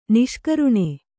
♪ niṣkaruṇe